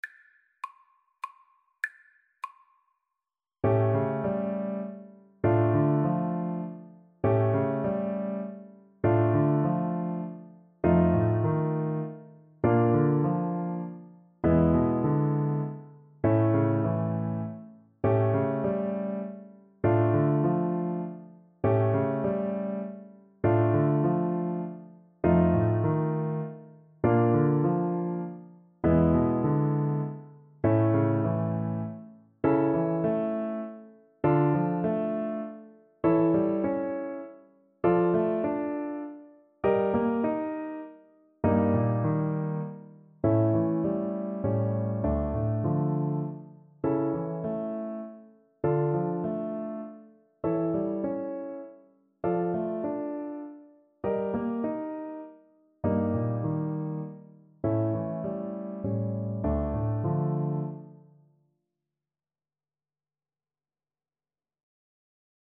Play (or use space bar on your keyboard) Pause Music Playalong - Piano Accompaniment Playalong Band Accompaniment not yet available transpose reset tempo print settings full screen
D minor (Sounding Pitch) (View more D minor Music for Flute )
3/4 (View more 3/4 Music)
Etwas bewegt
Classical (View more Classical Flute Music)